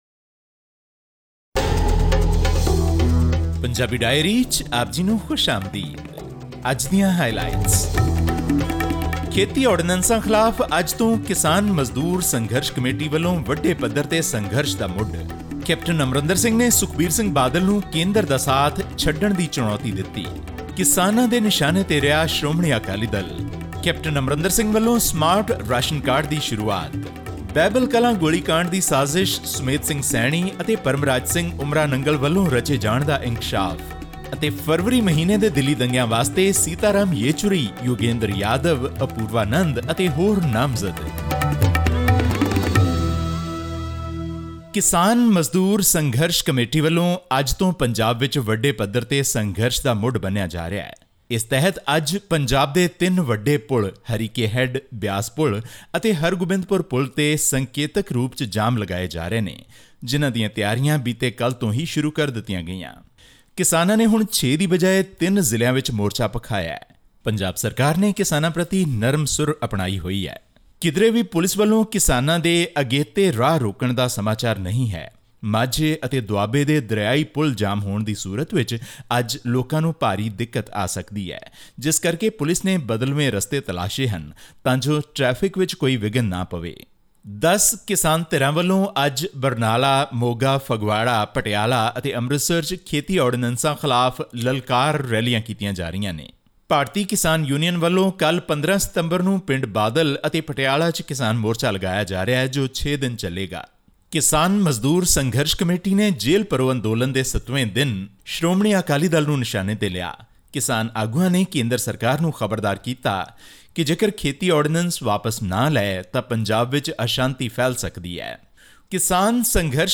In our weekly news wrap from Punjab, today we bring you the latest on the unrest amongst Punjab's farmers arising from the Modi government's agriculture ordinance, a twist in the Behbal Kalan firing case and Punjab's new Smart Ration Cards.